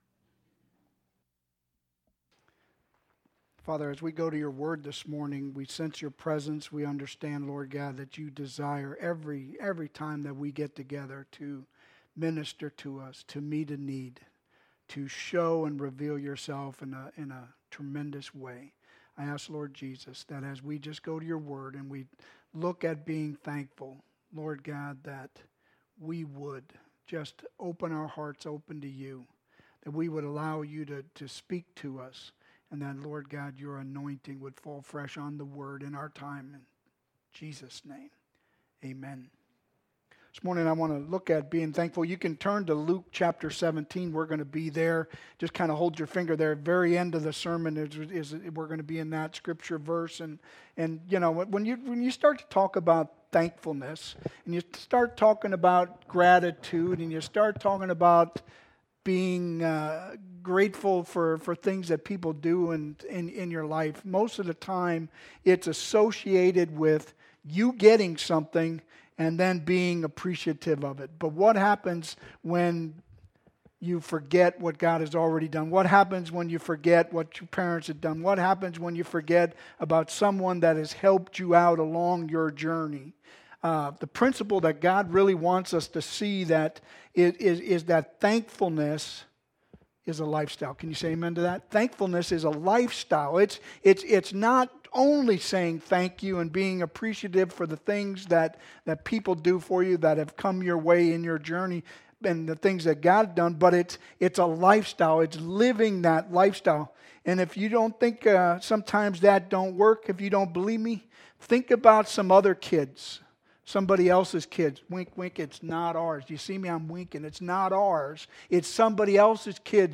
Sermons « Rosedale Church of the Nazarene